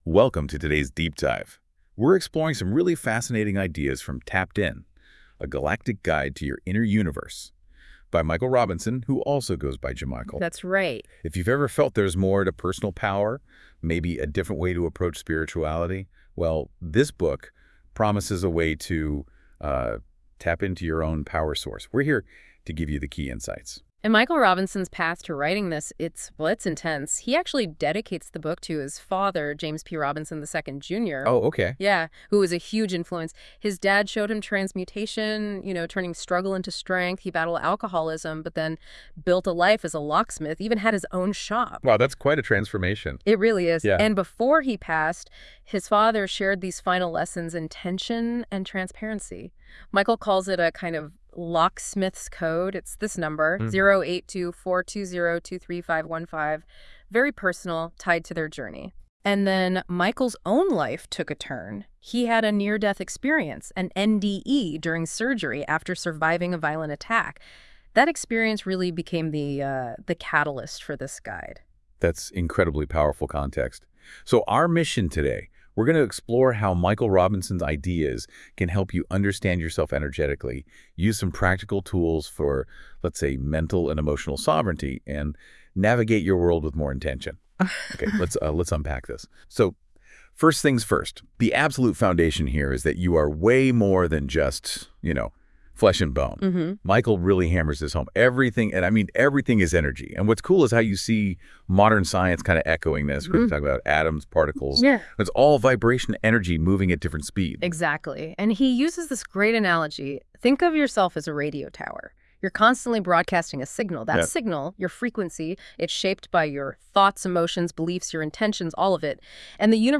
an (AI) tapped in discussion about TAPPED IN!!